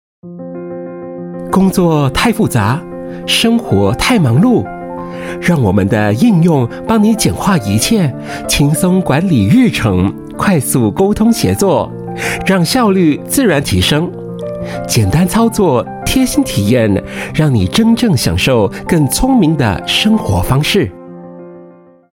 Voice Samples: StartUp
male